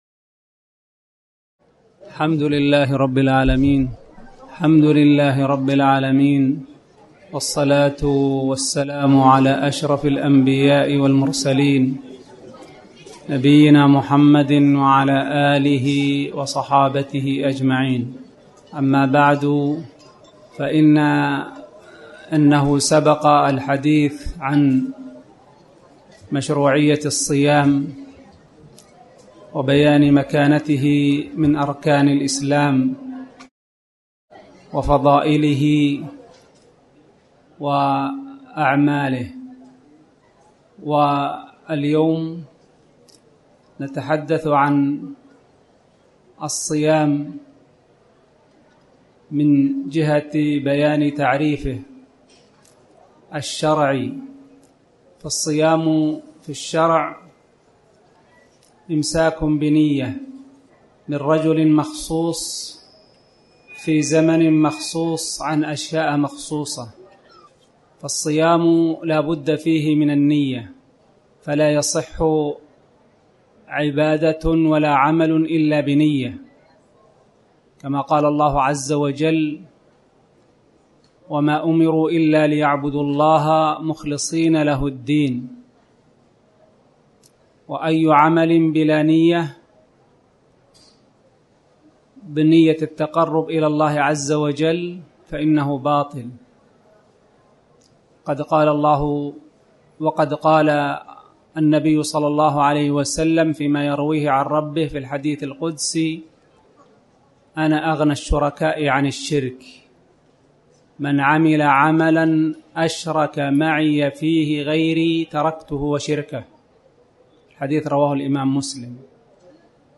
تاريخ النشر ٨ رمضان ١٤٣٩ المكان: المسجد الحرام الشيخ: فضيلة الشيخ عبدالله بن محمد آل خنين فضيلة الشيخ عبدالله بن محمد آل خنين أحكام الصيام